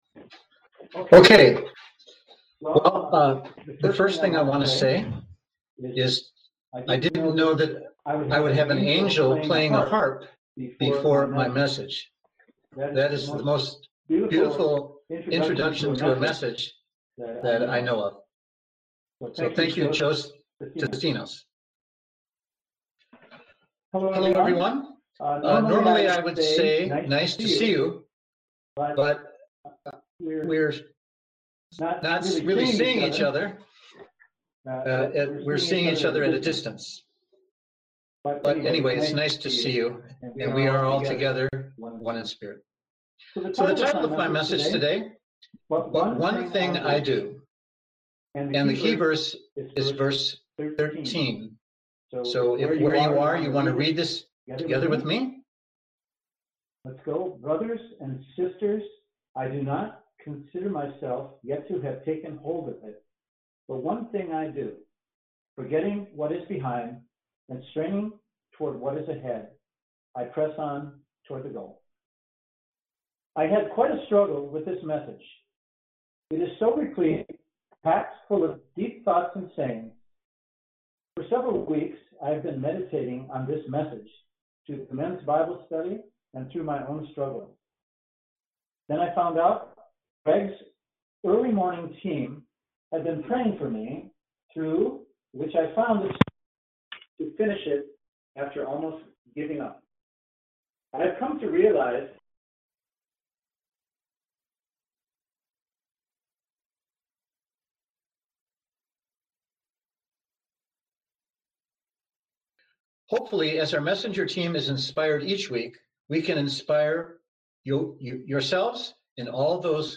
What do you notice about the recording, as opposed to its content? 2020 GLR Spring Conference